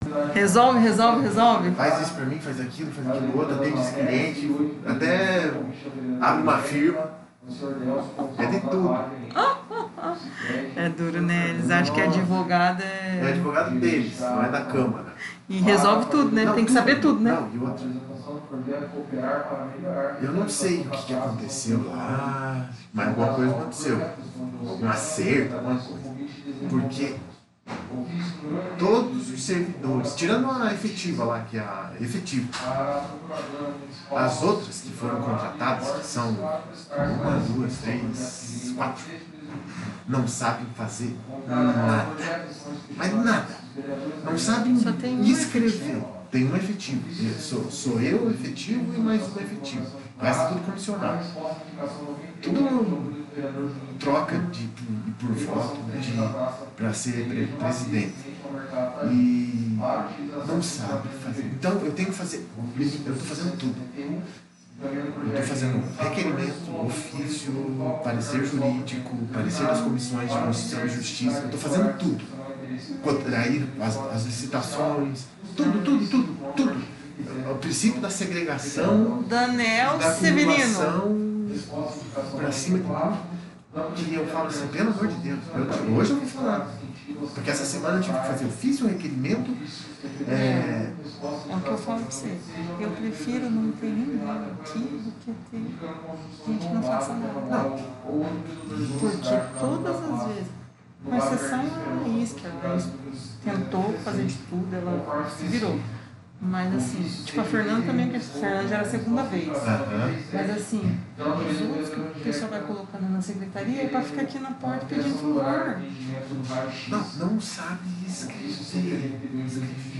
26ª Sessão Ordinária — Câmara Municipal de Tamarana